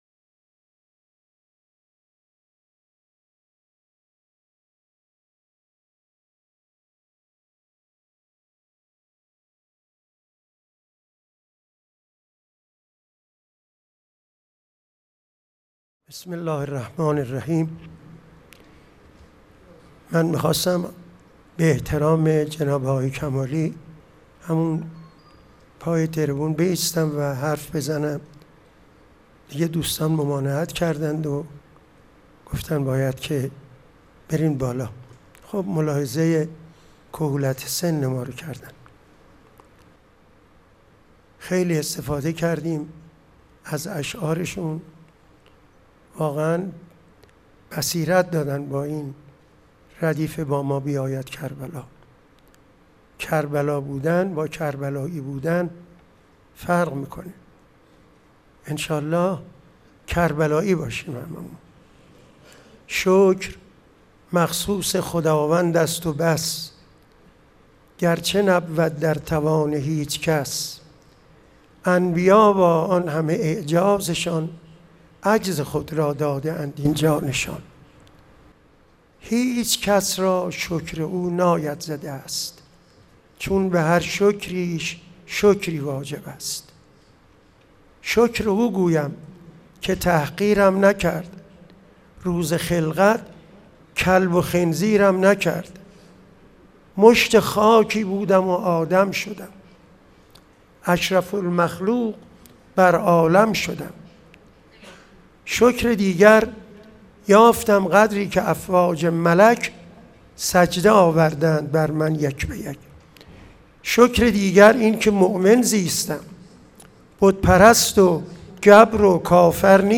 سخنرانی
پنجمین همایش هیأت‌های محوری و برگزیده کشور